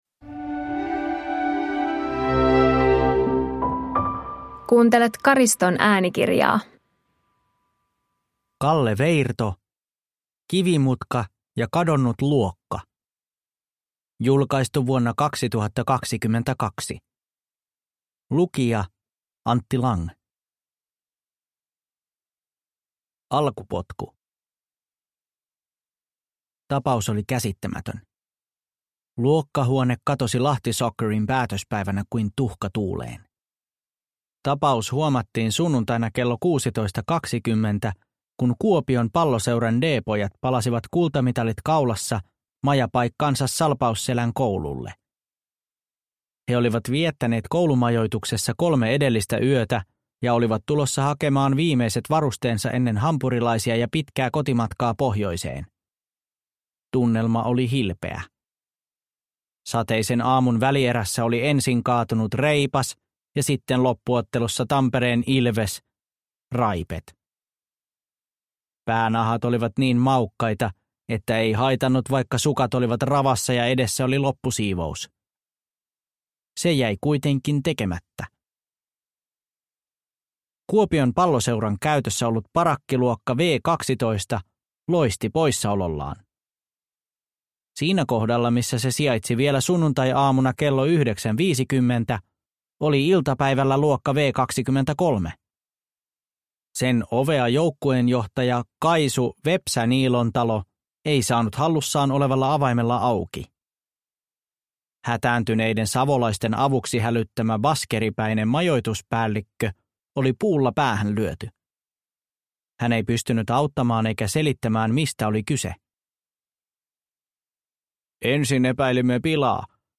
Kivimutka ja kadonnut luokka – Ljudbok – Laddas ner